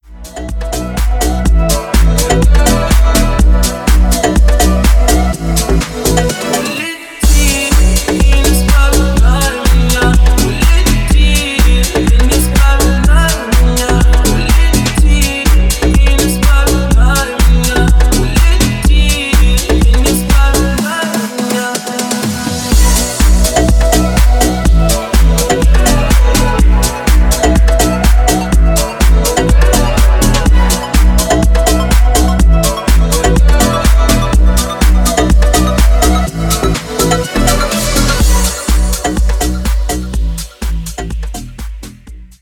• Качество: 320, Stereo
мужской вокал
deep house
dance
Club House